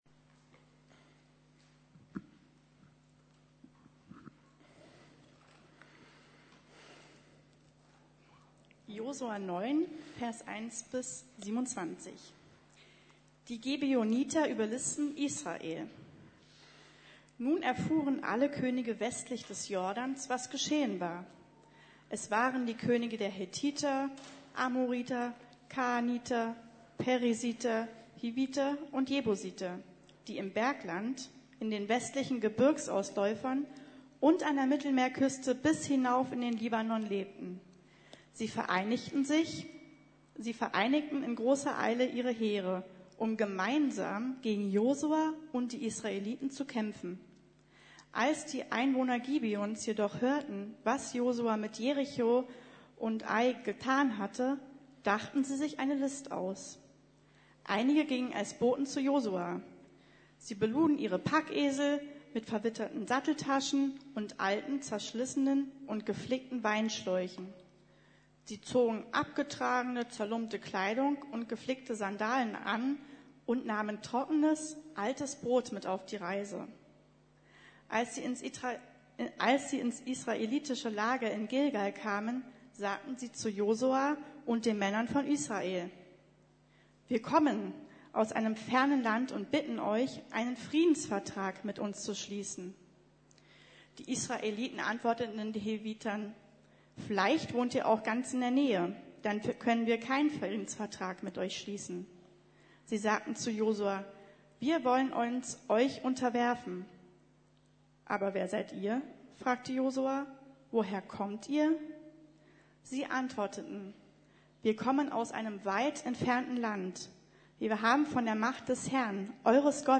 Jesus im Alten Testament, Teil 4: Unverdiente Gnade ~ Predigten der LUKAS GEMEINDE Podcast